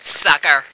flak_m/sounds/female2/int/F2sucker.ogg at trunk